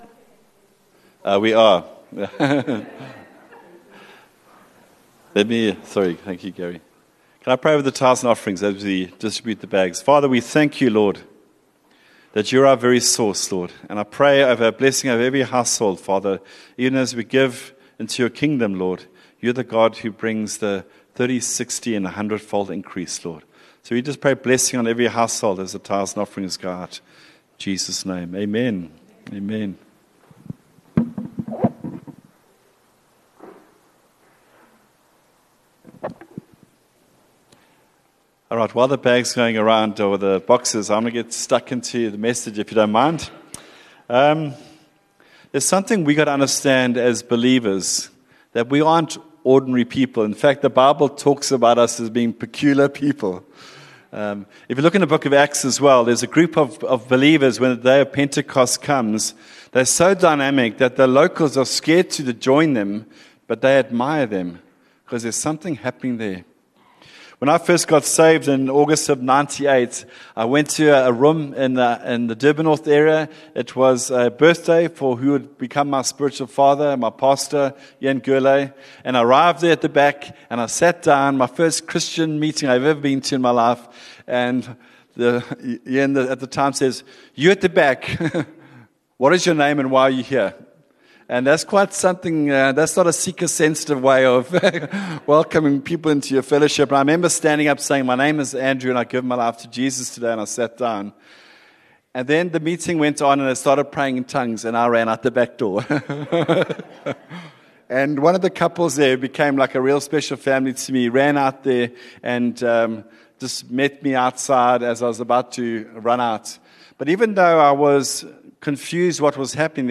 View Promo Continue JacPod Install Upper Highway Vineyard Sunday messages 8 Sep Preach - 08 Sep 2024 23 MIN Download (11.0 MB) Lean unto God in all your ways.